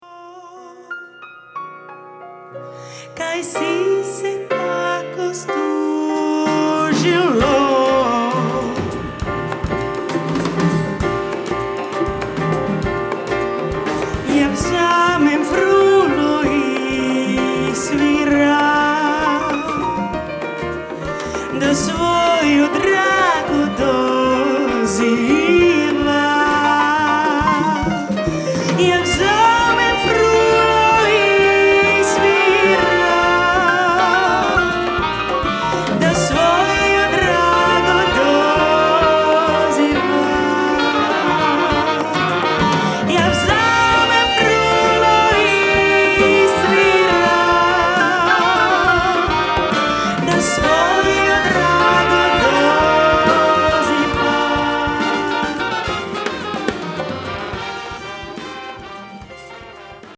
youth orchestra
BIG BAND